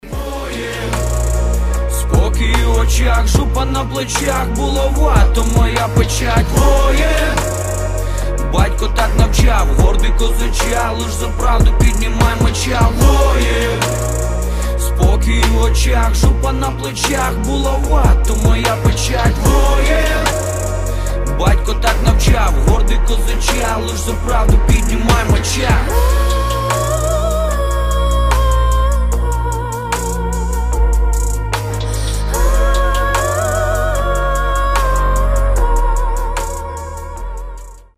• Качество: 320, Stereo
мужской вокал
брутальные
Rap
красивый женский голос
воодушевляющие
эпичные